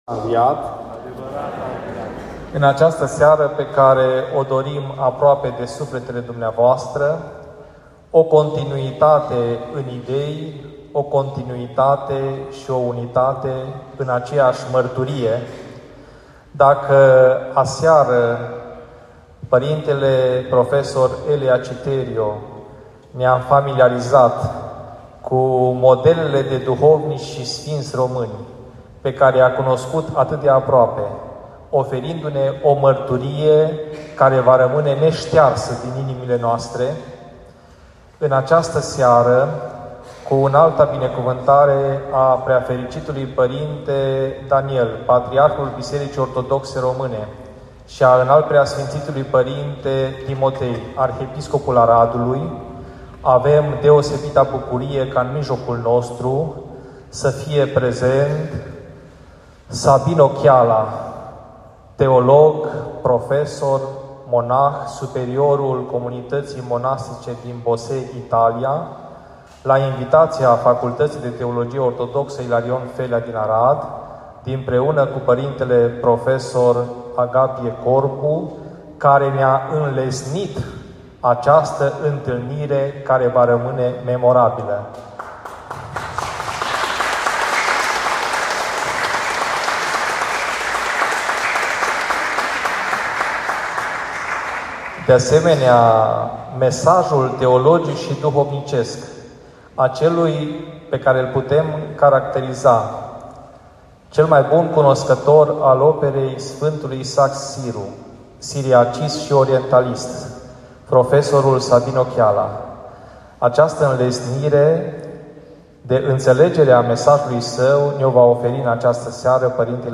Conferenza alla Sala comunale di Arad